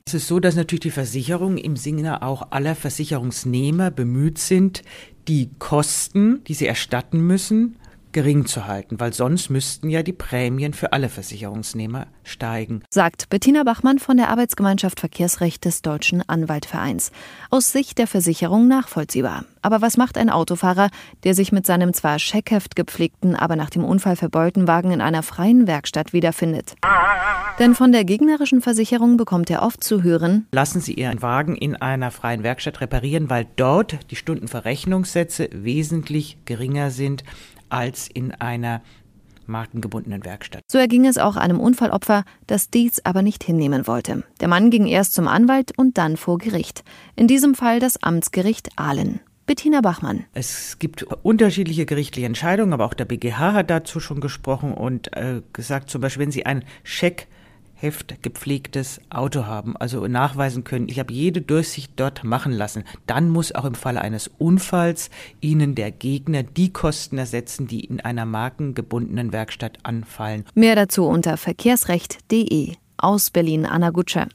O-Töne / Radiobeiträge, , ,